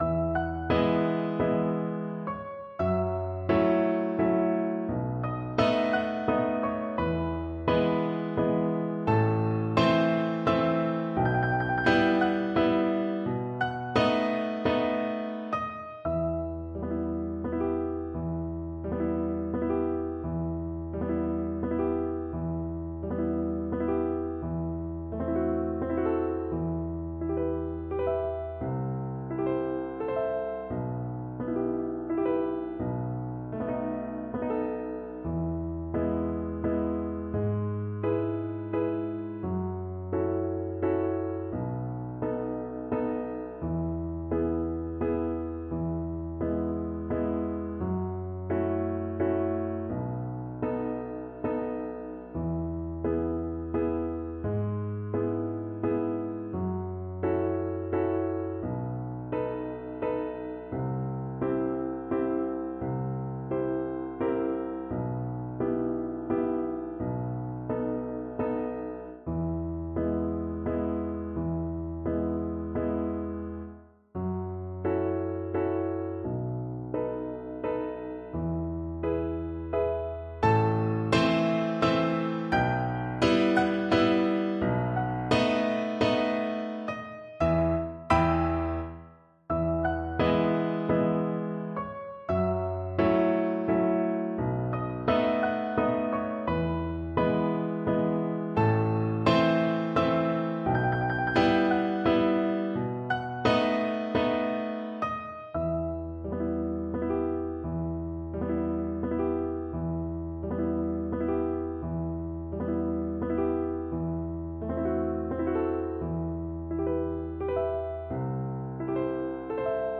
3/4 (View more 3/4 Music)
Andantino = c. 86 (View more music marked Andantino)
Neapolitan Songs for Violin